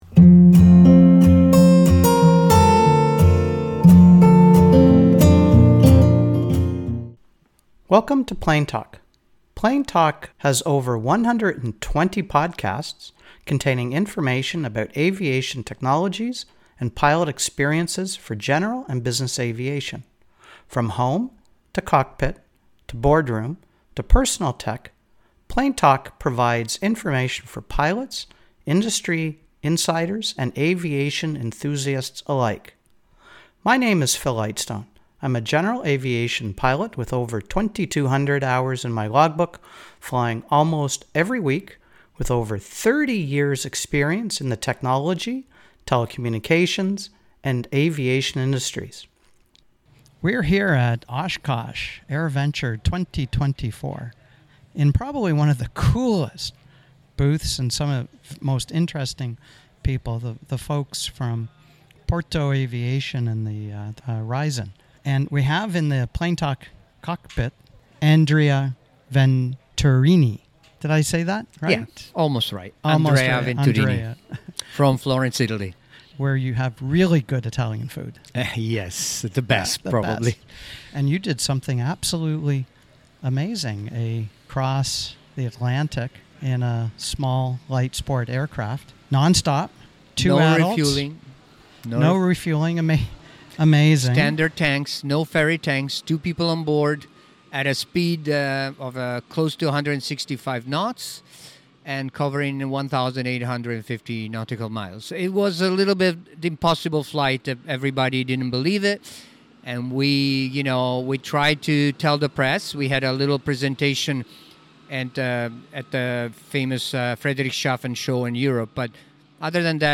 Risen at AirVenture 2024